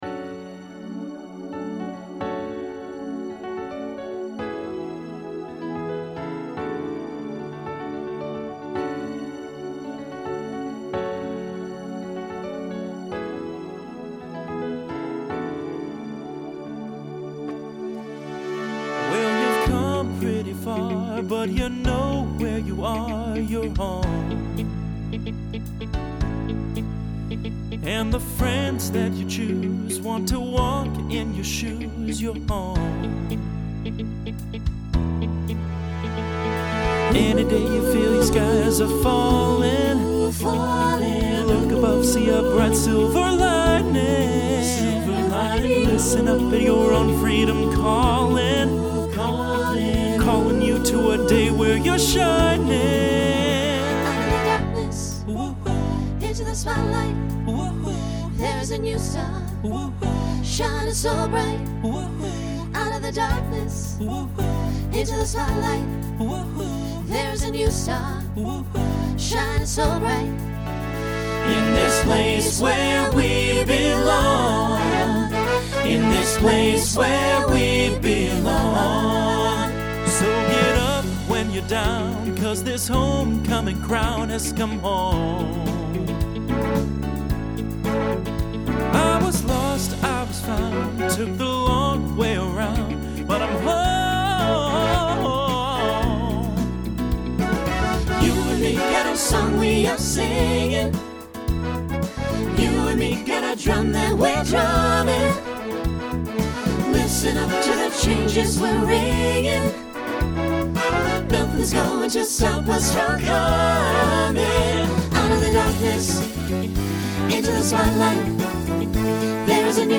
Genre Broadway/Film
Transition Voicing SATB